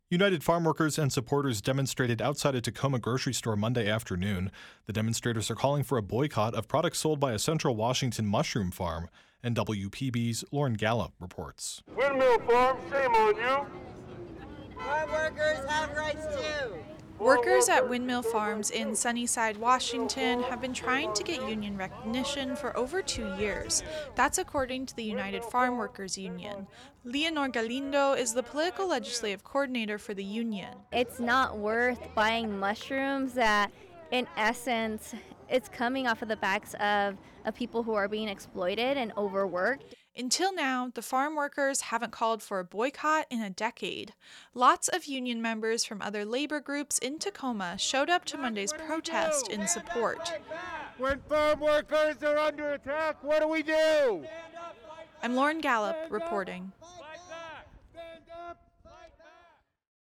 People traveled from across Washington state to rally outside of a Tacoma Safeway store in support of farm workers Monday afternoon.
UFW-demonstration-web_mixdown.mp3